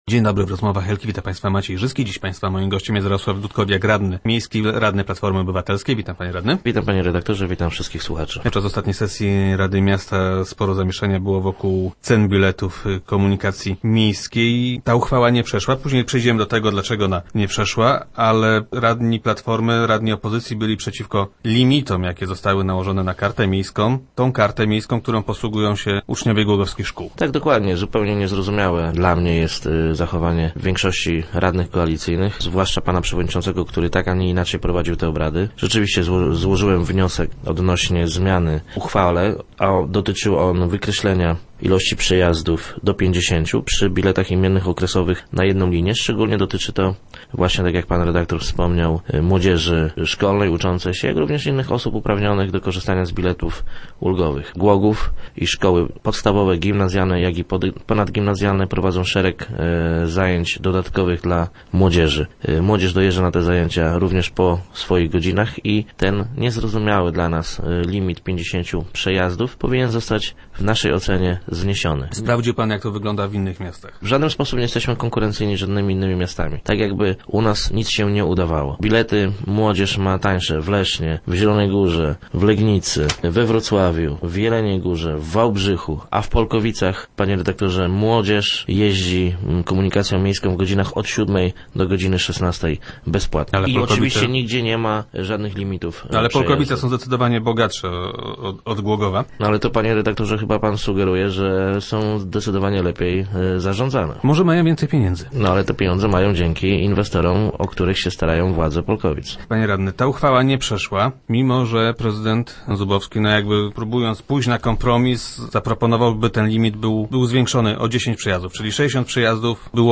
Gościem piątkowych Rozmów Elki był Jarosław Dudkowiak, radny Platformy Obywatelskiej.